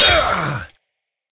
SH_hit3.ogg